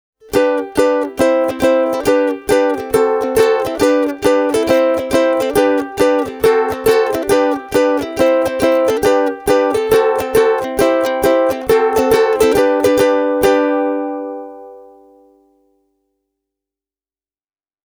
Linjasoitostakin lähtee laadukas versio pienokaisen akustisesta äänestä: